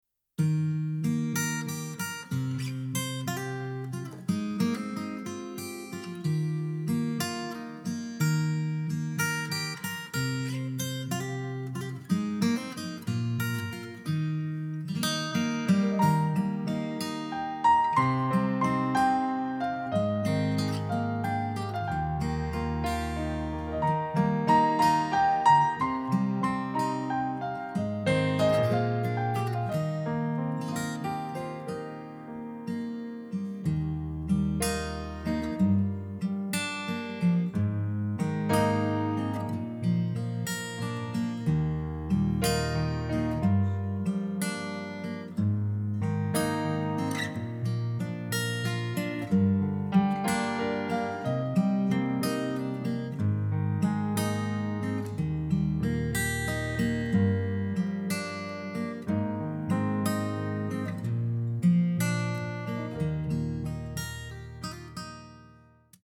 伴奏信息
歌曲调式：降E调